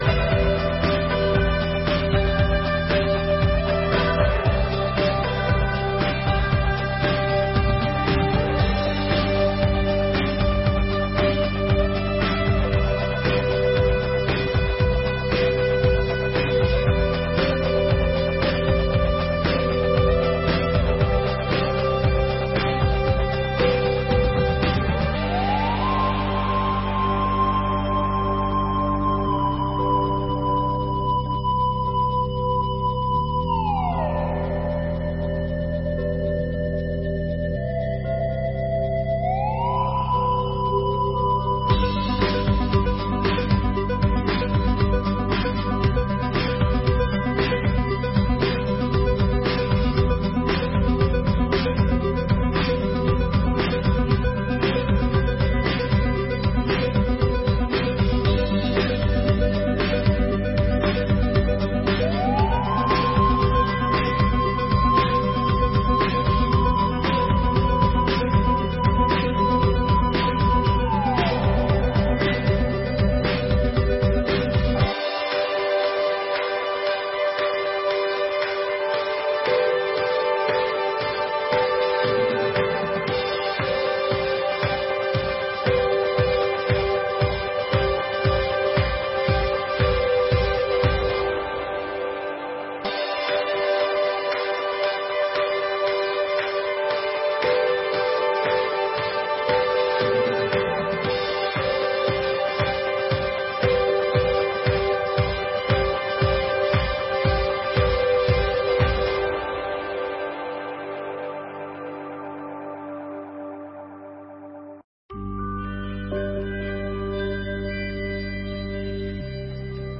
Sessões Solenes de 2022